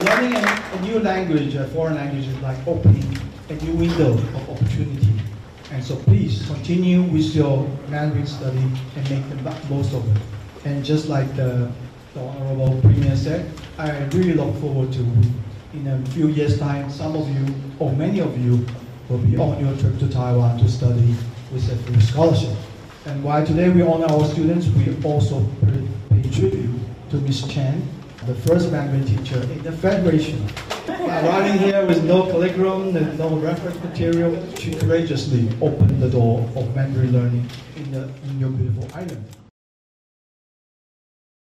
In a celebration of academic achievement, cultural exchange, and international friendship, the island of Nevis recently hosted the Fourth Annual Awards Ceremony for the Test of Chinese as a Foreign Language (TOCFL).
The Ambassador made these remarks: